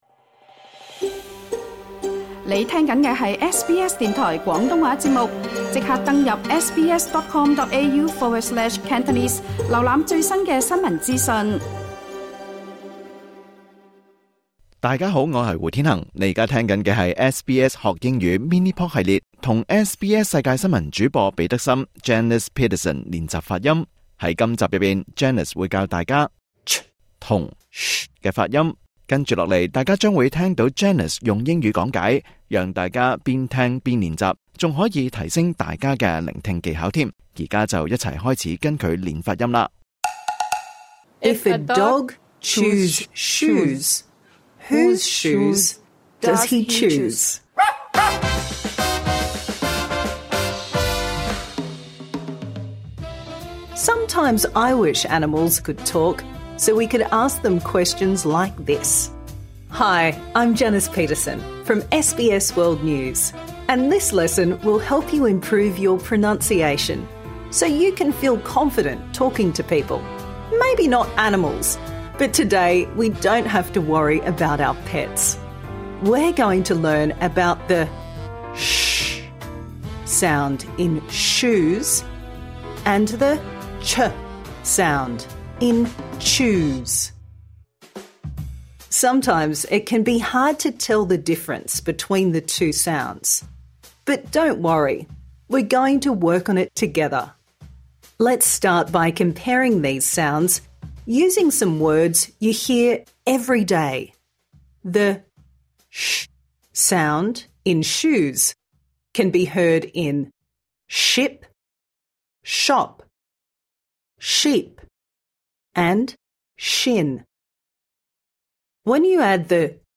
Improve your pronunciation | Season 2